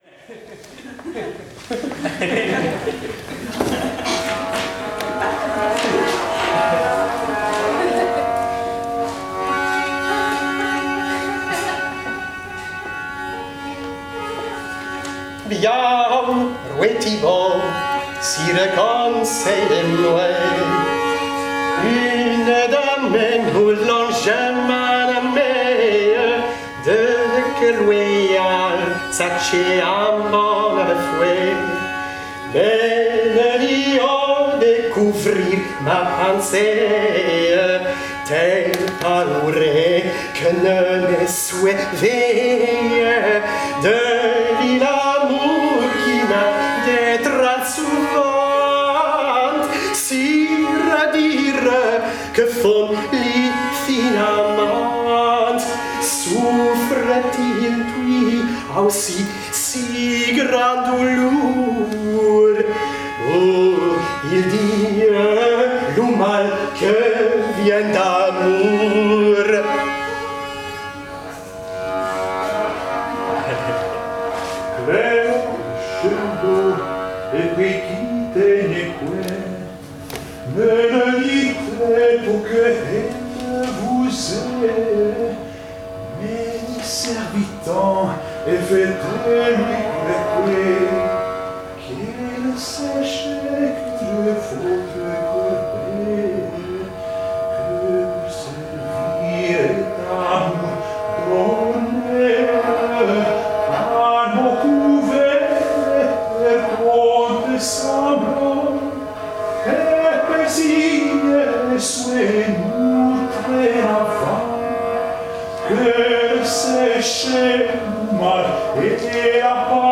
A third post from the performance workshop with graindelavoix, sponsored by the Leverhulme Trust and held at St Hugh’s College, Oxford in March 2017.